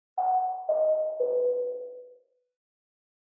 Station Announcement Bell Sound Effect – Public Platform Alert Tone
A clear notification bell rings at train and bus stations, airports, or public spaces. The bell grabs attention and signals announcements. You hear the familiar alert sound echoing across the platform or hall.
Station-announcement-bell-sound-effect-public-platform-alert-tone.mp3